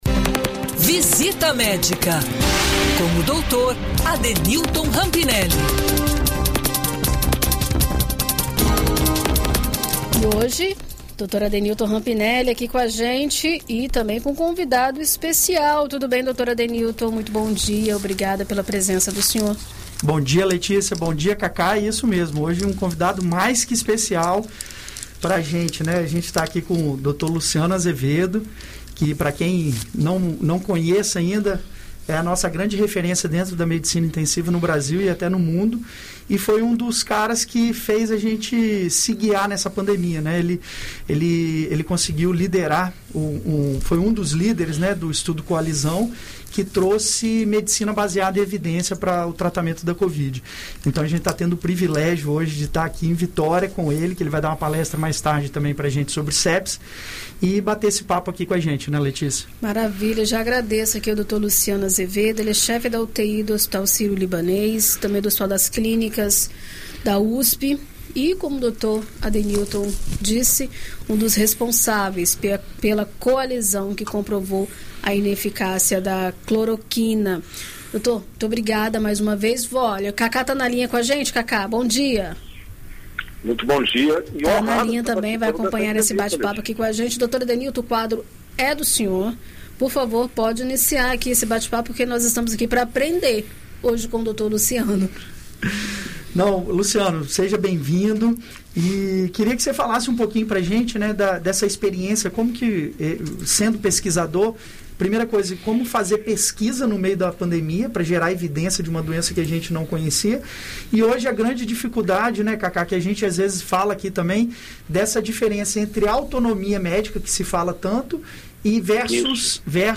em uma conversa sobre o chamado "kit covid"